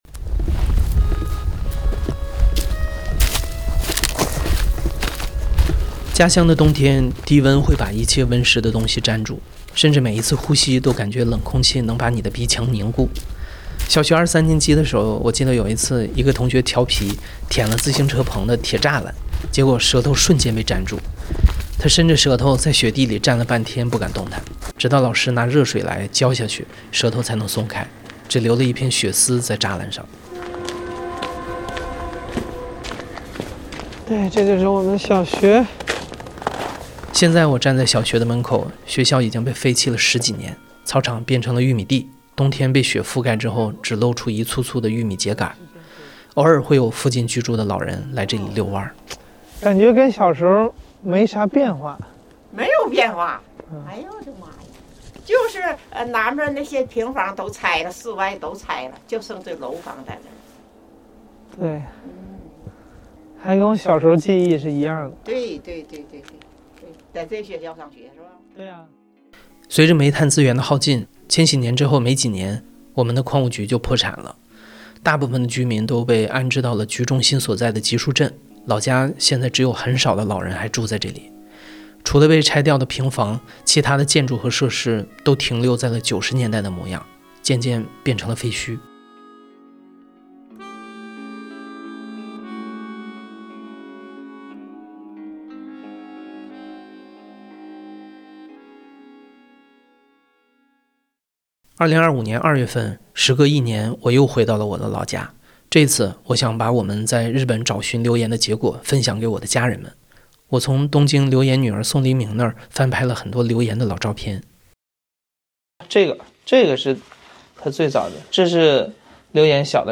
故事FM ❜ 2025年度大型系列声音纪录片 家乡的冬天，低温会把一切温湿的东西粘住，甚至每一次呼吸都感觉冷空气能让你的鼻腔凝固。
故事FM 是一档亲历者自述的声音节目。